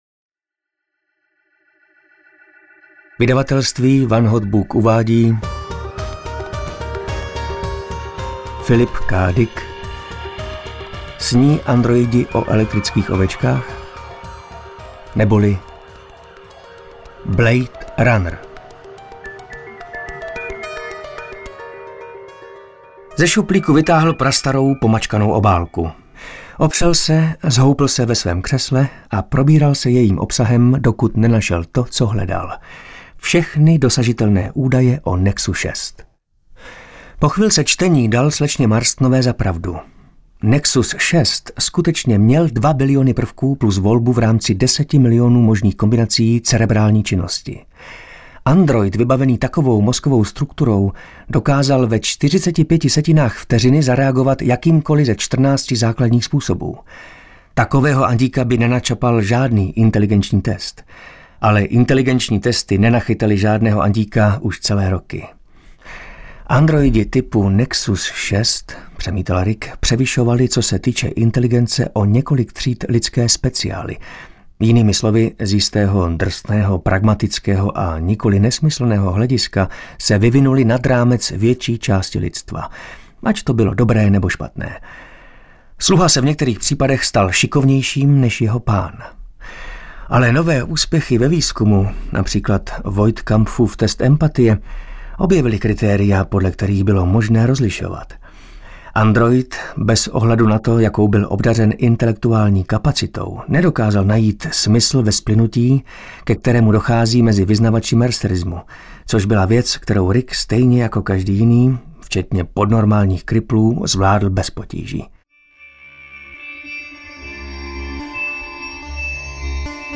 Blade Runner audiokniha
Ukázka z knihy